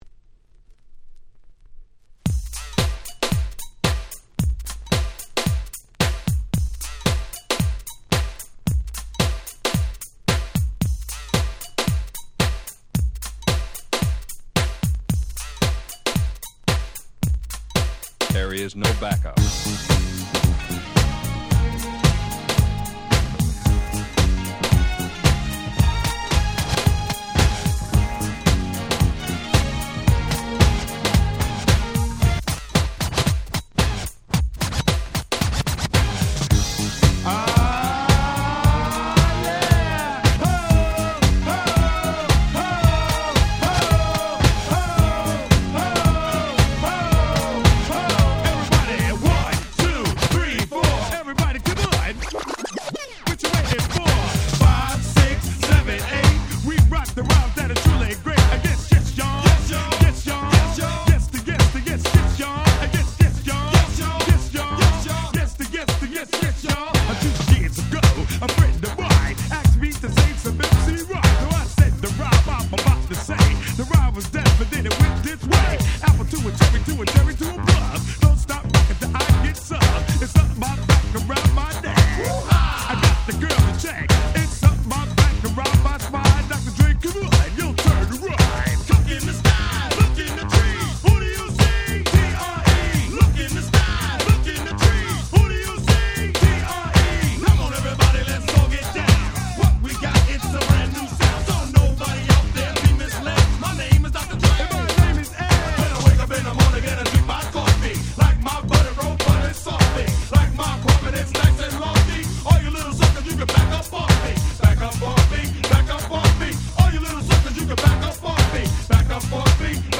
【Media】Vinyl 12'' Single
自分が当時好んで使用していた曲を試聴ファイルとして録音しておきました。